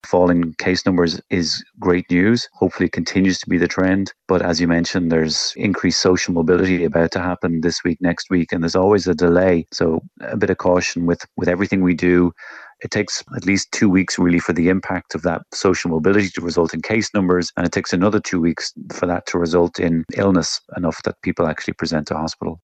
A consultant in infectious diseases says the number of Covid patients in hospital could continue to rise, despite falling case numbers.